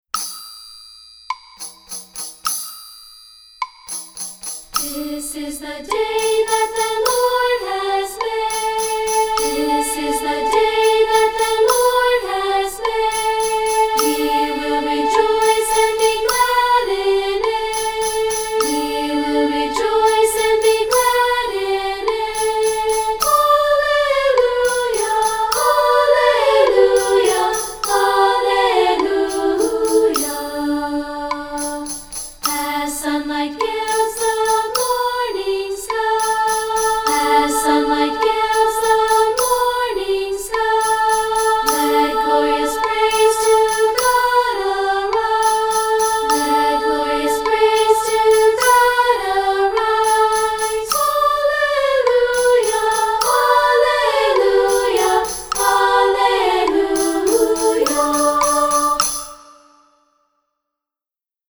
Anthems for Treble Voices
Unison/two-part with Orff instruments and percussion